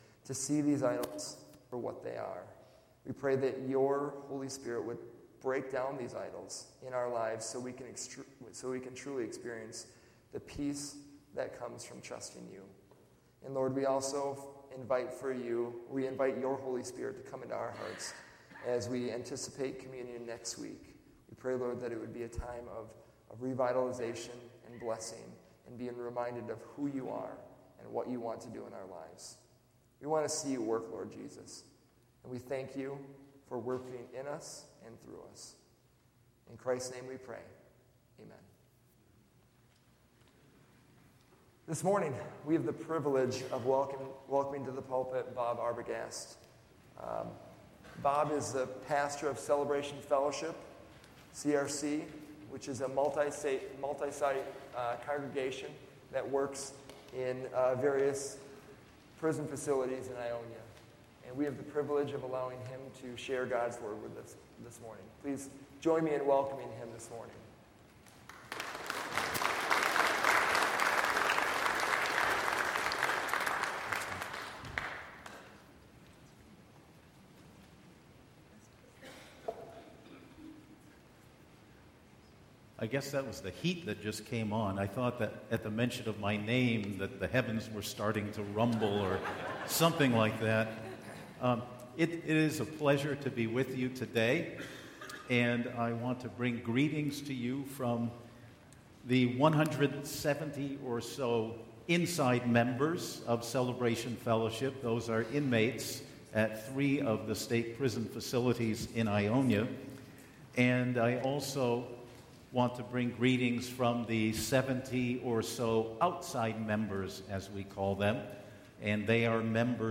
October 21, 2018 A Theives’ Banquet Series: Mission Emphasis 2020 Passage: Ephesians 4:25-5:2, John 6:35, 31-51 Service Type: Sunday AM Download Files Bulletin « Lecturing God on How to Fix Us!